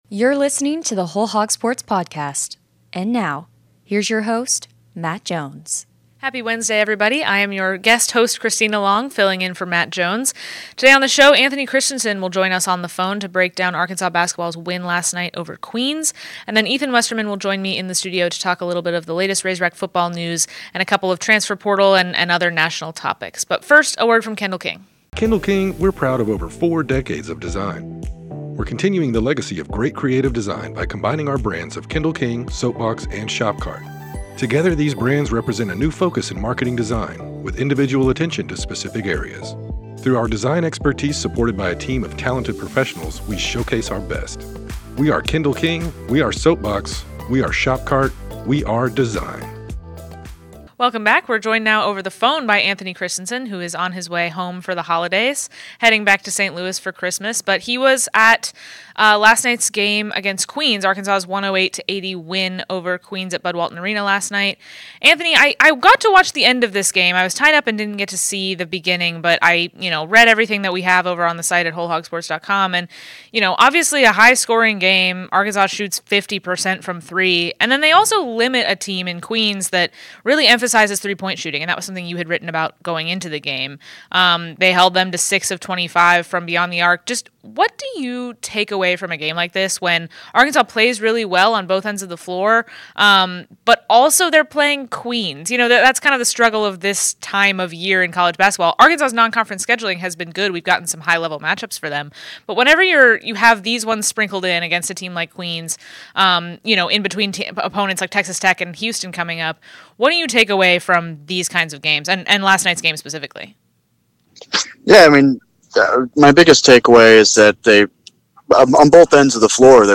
is in the studio